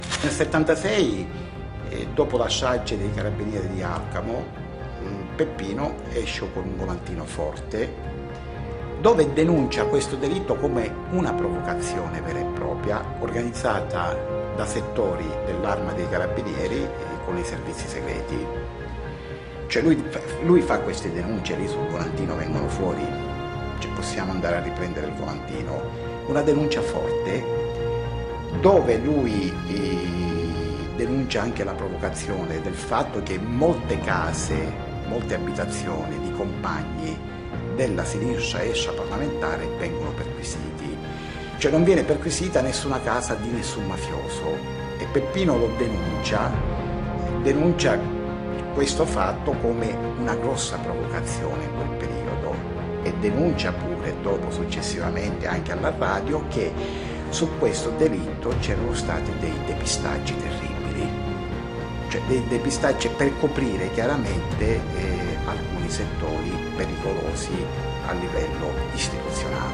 Quelle che ascolteremo di seguito sono le voci tratte da un documento filmato che è facilmente reperibile in rete.